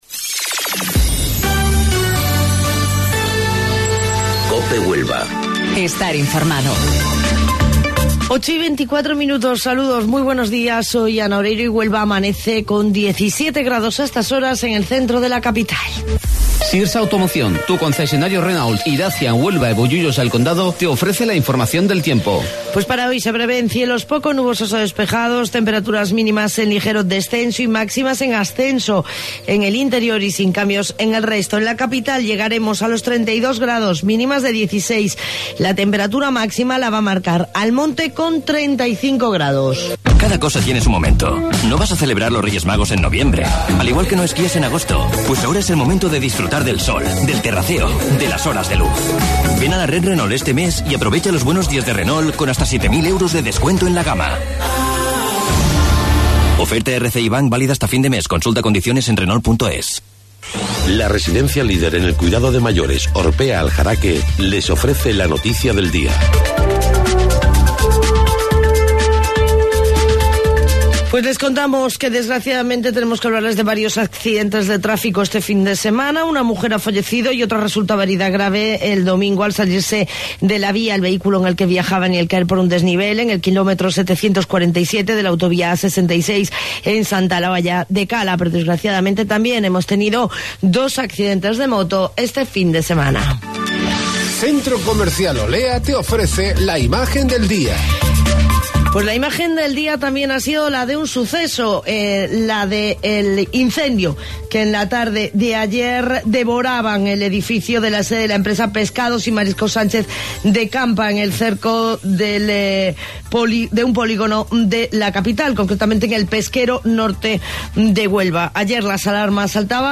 AUDIO: Informativo Local 08:25 del 13 de Mayo